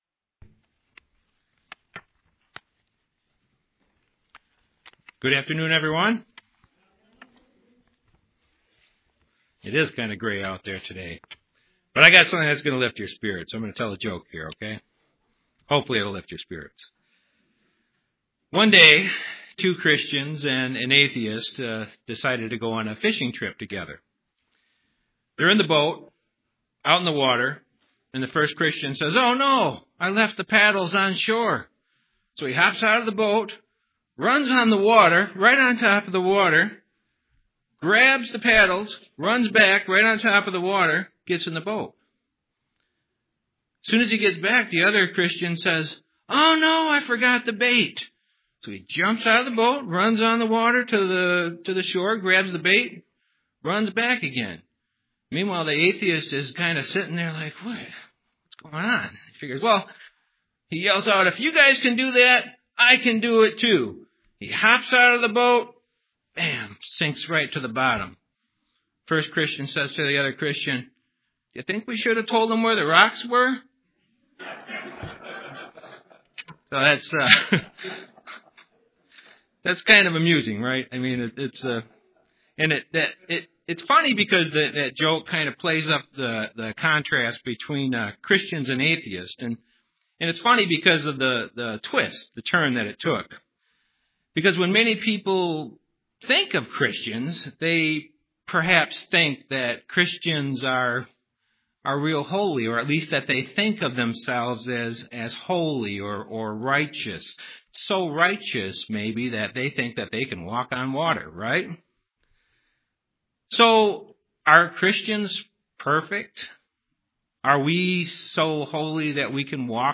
Listen in to this split sermon to find out.
Given in Grand Rapids, MI